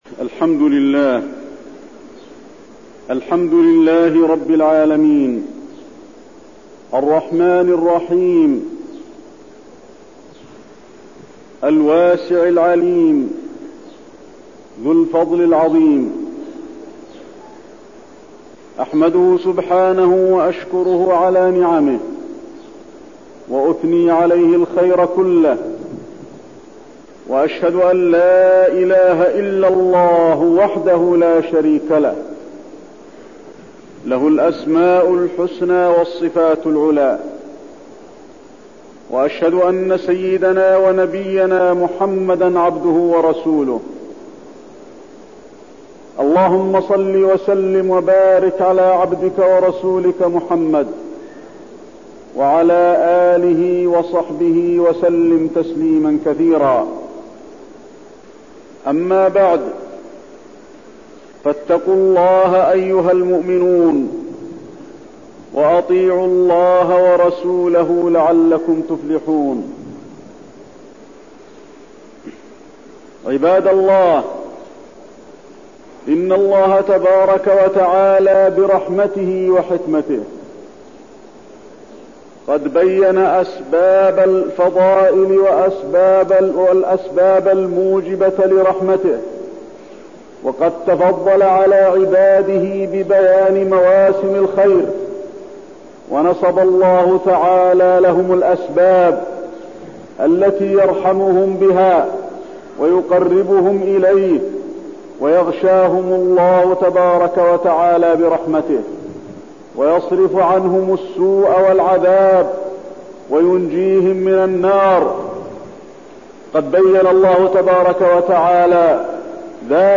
تاريخ النشر ٢٦ شعبان ١٤٠٧ هـ المكان: المسجد النبوي الشيخ: فضيلة الشيخ د. علي بن عبدالرحمن الحذيفي فضيلة الشيخ د. علي بن عبدالرحمن الحذيفي فضل شهر رمضان